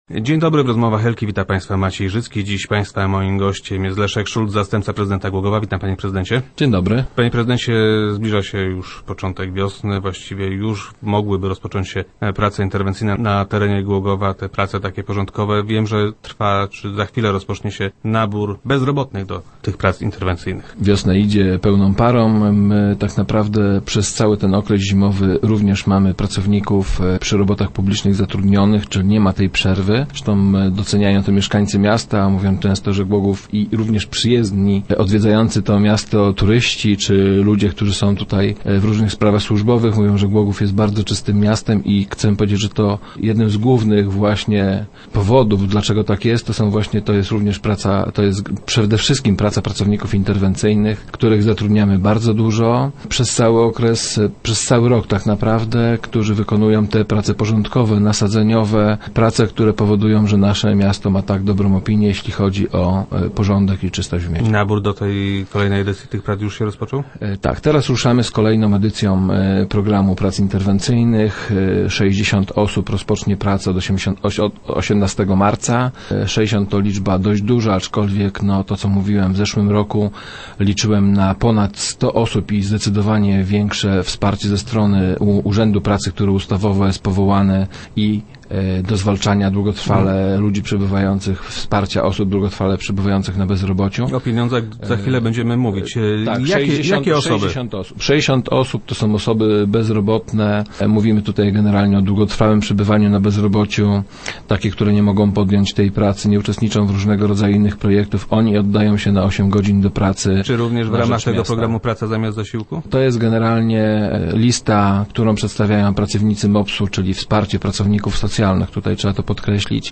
- Sześćdziesiąt osób to całkiem sporo, ale miałem nadzieję, że uda nam się zatrudnić ponad stu bezrobotnych. Liczyłem też na zdecydowanie większe wsparcie ze strony Powiatowego Urzędu Pracy, który ustawowo powołany jest do wspierania osób długotrwale pozostających bez zatrudnienia - mówił wiceprezydent Szulc, który był gościem Rozmów Elki.